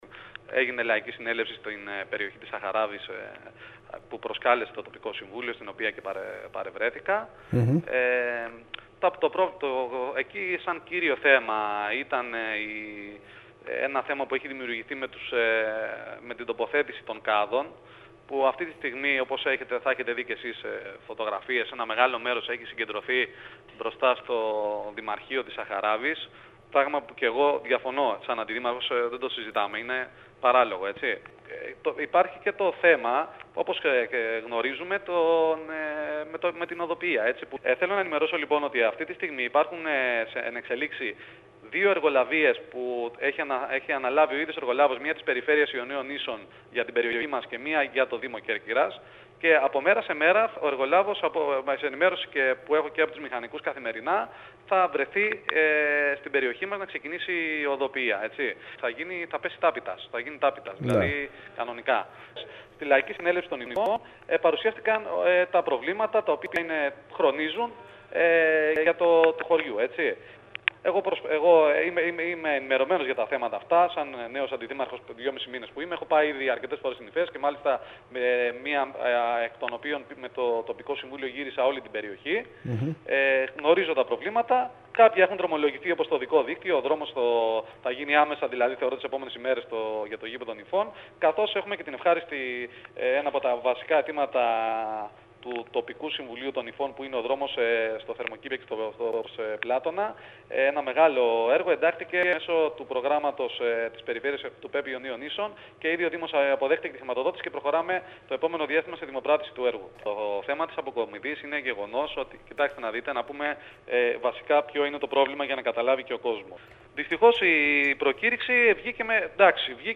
Ο Αντιδήμαρχος Βόρειας Κέρκυρας Μάρκος Πρεντουλής, μιλώντας στο σταθμό μας, τόνισε ότι υπήρξε ενημέρωση για τα οδικά έργα που θα ξεκινήσουν άμεσα και θα αφορούν τόσο τις τουριστικές περιοχές όσο και τα χωριά των ΔΔ Θιναλίων και Κασσωπαίων.